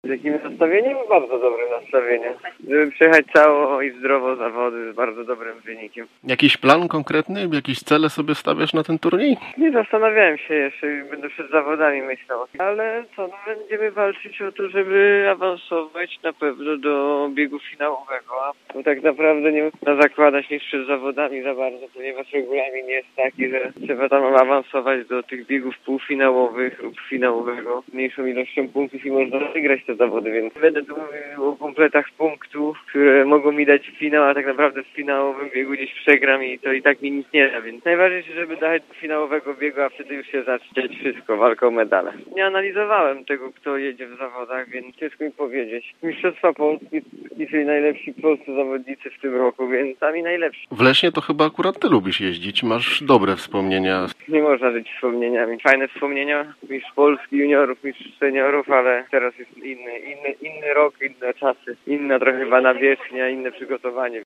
W rozmowie z nami powiedział, z jakim nastawieniem wybiera się do Wielkopolski. Ponadto „Duzers” podkreślił, że najważniejsze jutro będzie wejście do finału: